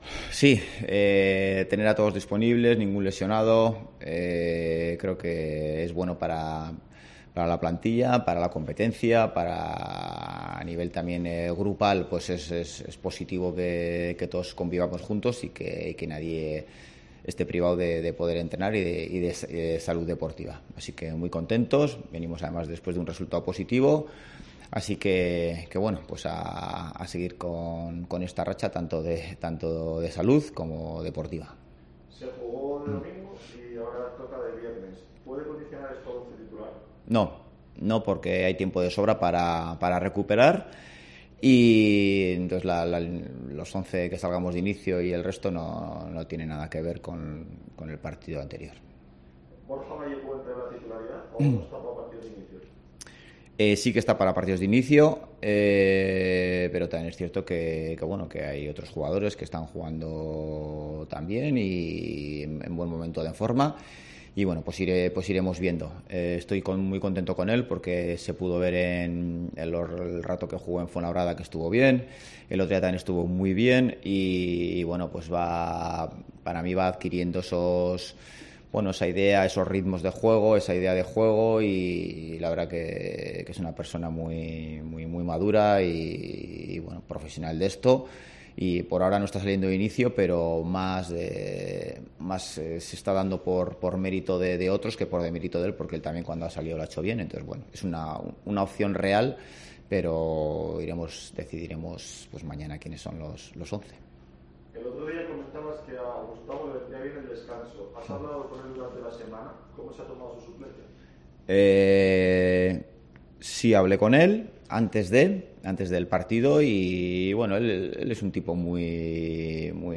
Rueda de prensa Ziganda (previa Espanyol-Oviedo)